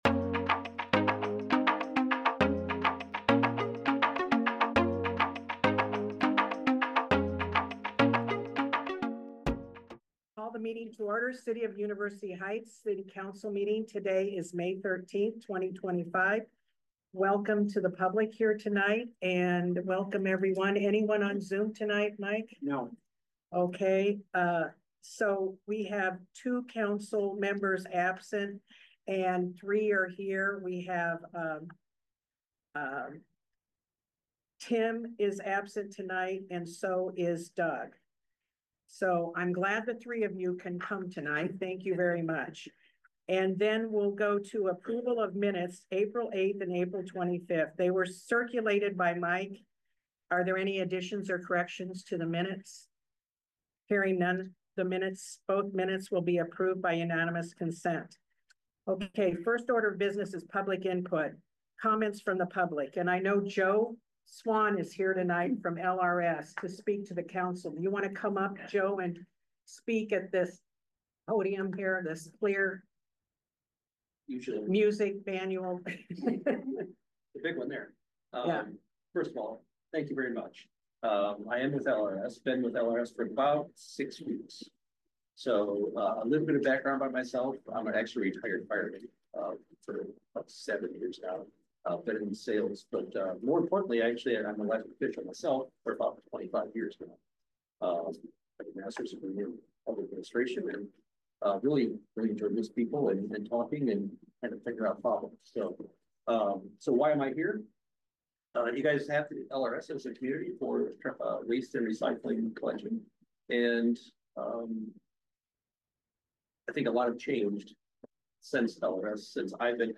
The monthly University Heights City Council Meeting.